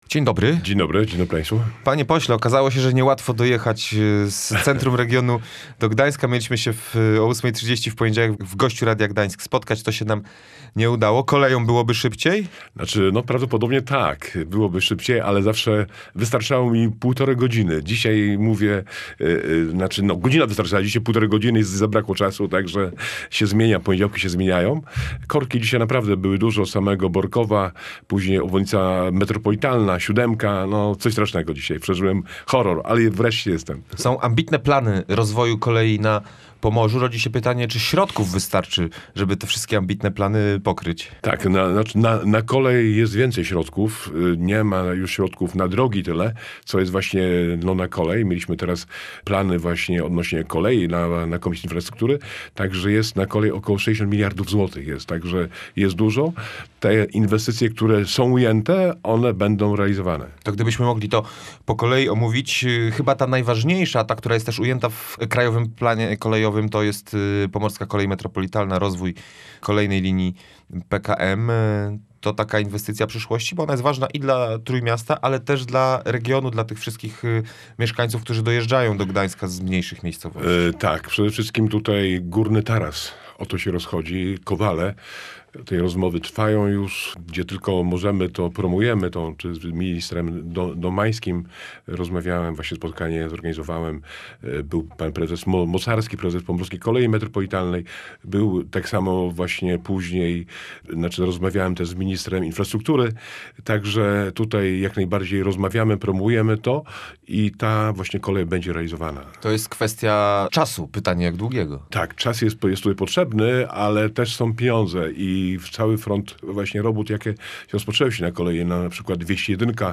Jak zapowiada poseł Koalicji Obywatelskiej Stanisław Lamczyk w rozmowie z Radiem Gdańsk, na kolej przeznaczono potężne
W audycji „Gość Radia Gdańsk” parlamentarzysta podkreślił, że to właśnie transport szynowy staje się głównym obszarem inwestycji państwa.